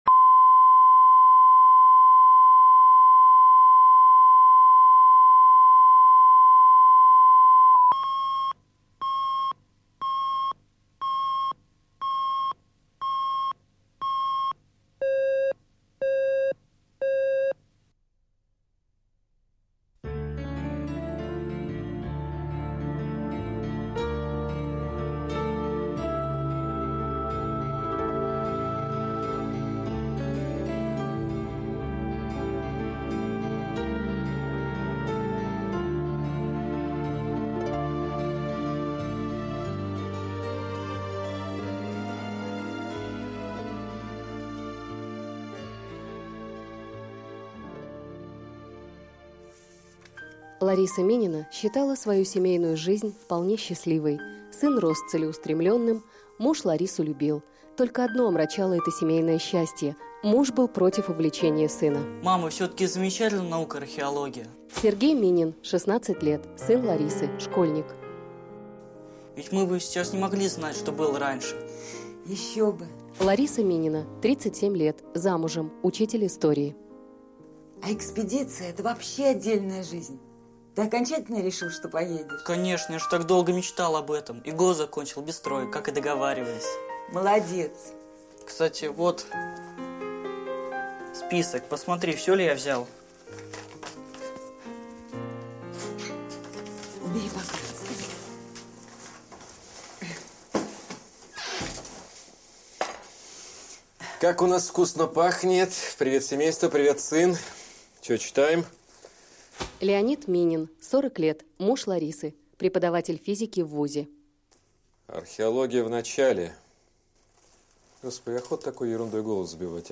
Аудиокнига Группа крови | Библиотека аудиокниг